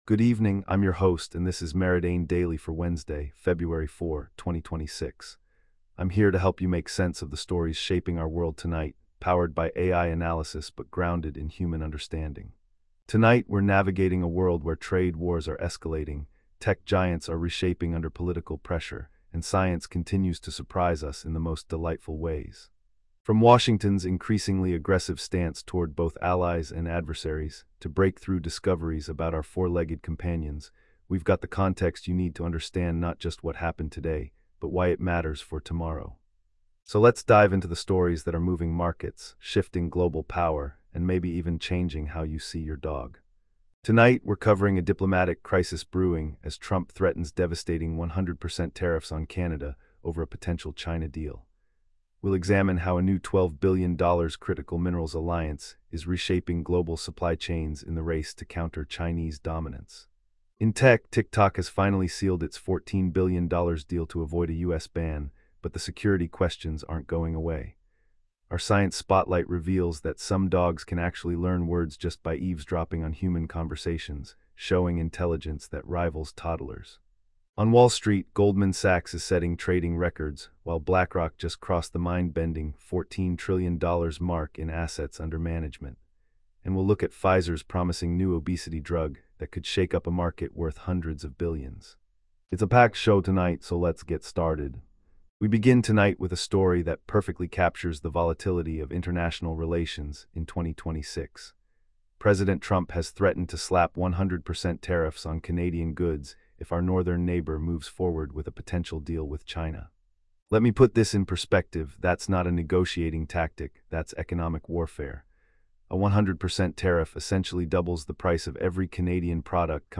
Your nightly AI-powered news briefing for Feb 4, 2026